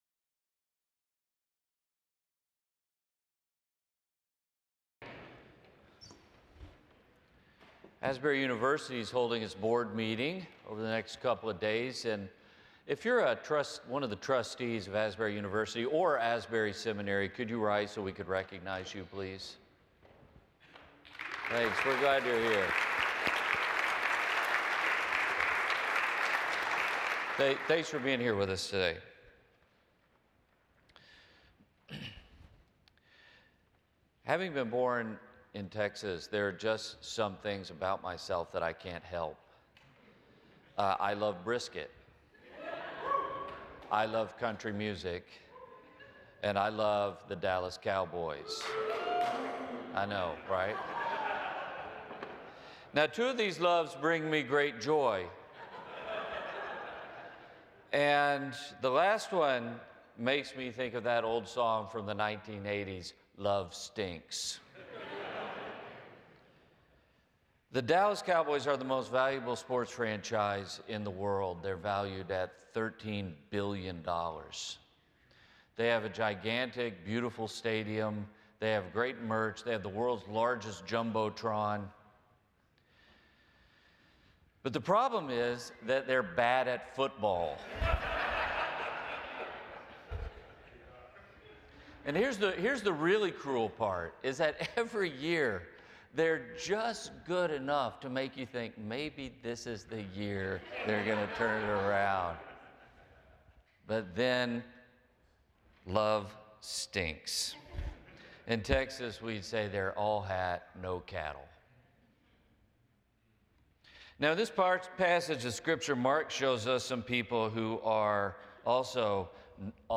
The following service took place on Thursday, March 5, 2026.